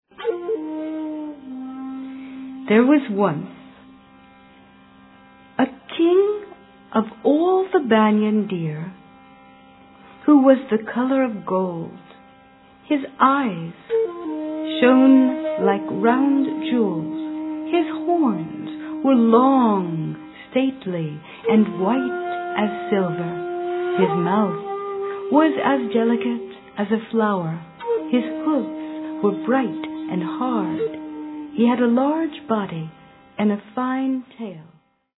The origins of the tales are described and illustrated in this colorul package, and each story is accompanied by its own authentic world music backdrop.
These and other masters weave a rich, poly-cultural tapestry of traditional music on instruments native to each story's culture.